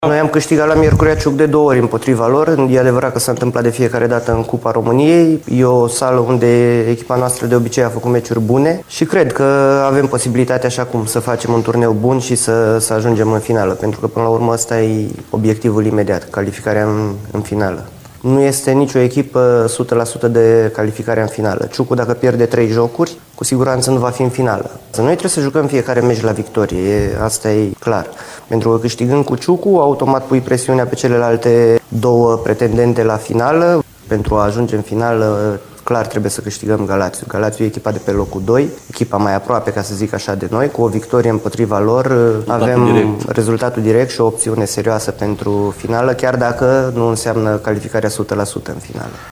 Devenii nu mai au dreptul la pași greșiți, dacă își doresc să joace finala. Invitat la UNU TV